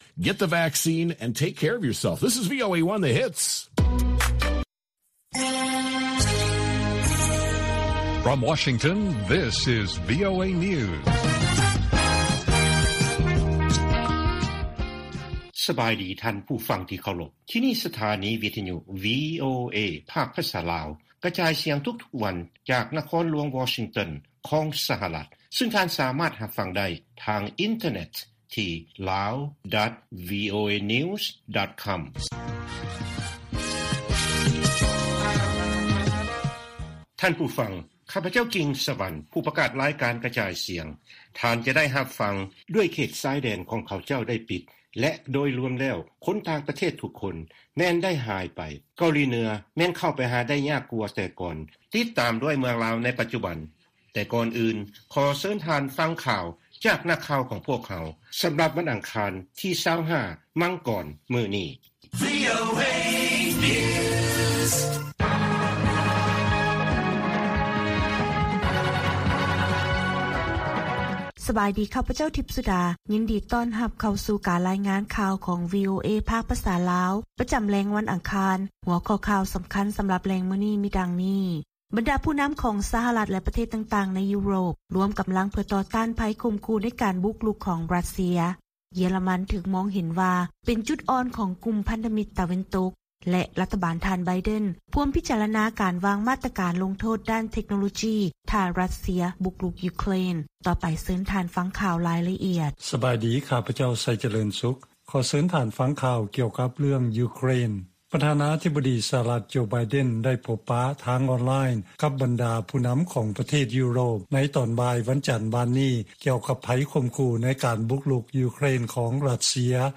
ລາຍການກະຈາຍສຽງຂອງວີໂອເອ ລາວ: ບັນດາຜູ້ນຳຂອງ ສະຫະລັດ ແລະປະເທດຕ່າງໆໃນຢູໂຣບ ລວມກຳລັງເພື່ອຕໍ່ຕ້ານໄພຂົ່ມຂູ່ ໃນການບຸກລຸກຂອງຣັດເຊຍ